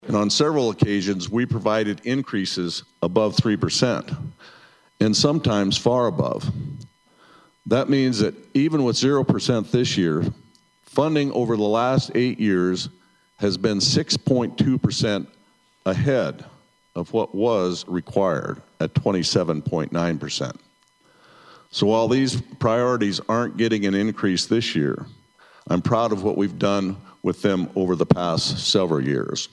PIERRE, (KCCR) — South Dakota Governor Larry Rhoden presented his first solo budget to a joint session of the South Dakota Legislature Tuesday at the State Capitol in Pierre. Rhoden says while there will be no increase for state employee they have received a number of large raises over the last decade…